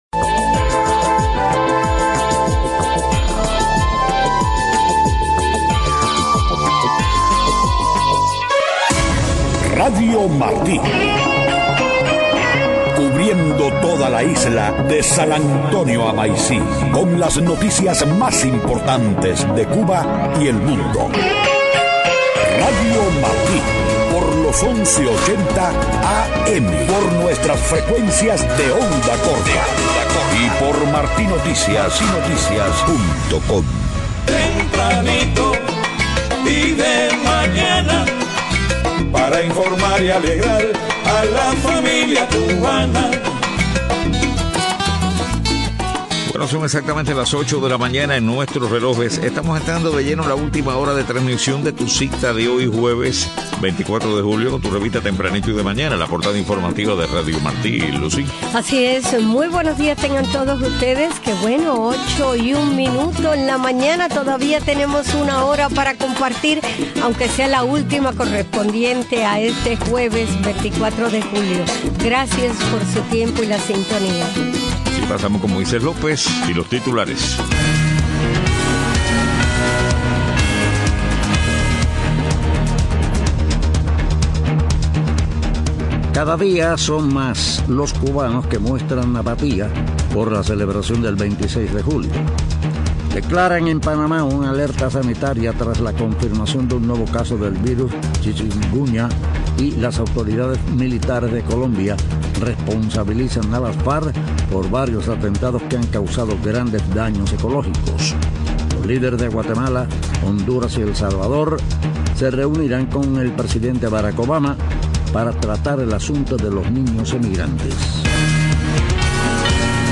8:00 a.m. Noticias: Crece en Cuba la apatía de la población por la celebración del 26 de julio. Declaran en Panamá una alerta sanitaria tras confirmación de nuevo caso de chinkunguña.